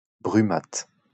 Brumath (French pronunciation: [bʁymat]